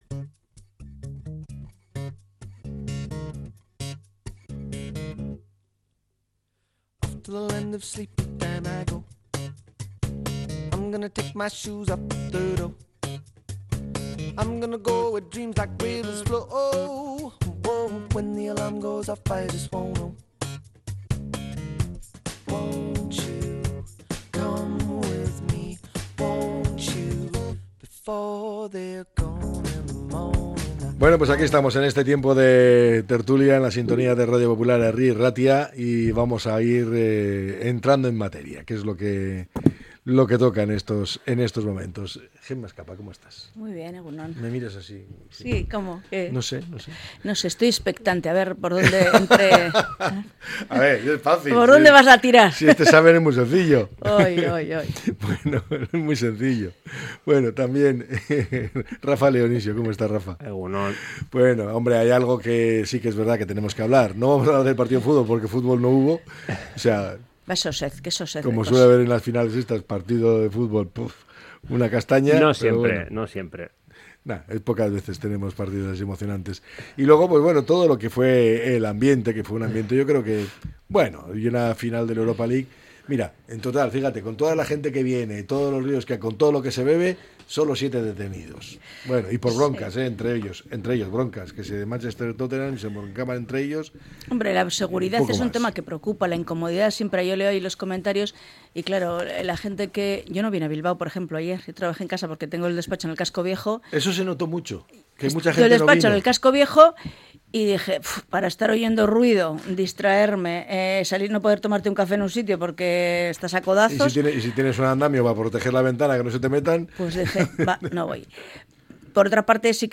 La tertulia 22-05-25.